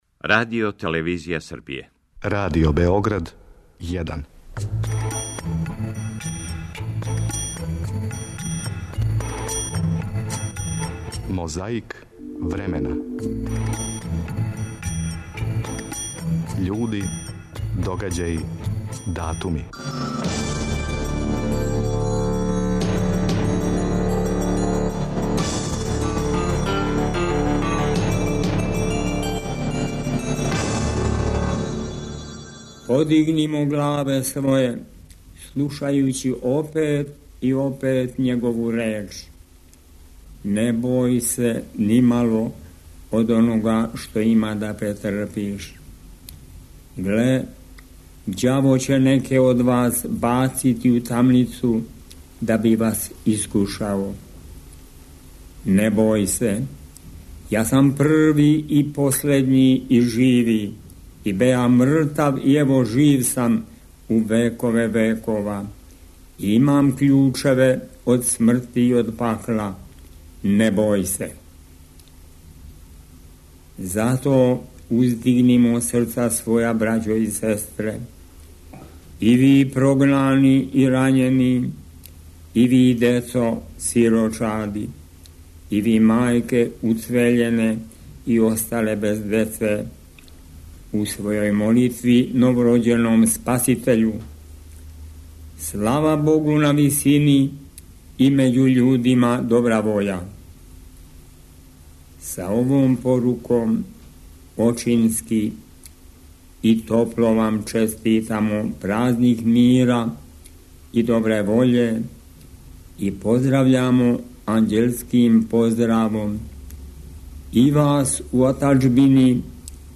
У јануару 1993. они у се нашли у Женеви на једној од мировних конференција, па ћемо чути и њих.